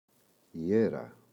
αίρα, η [Ꞌera]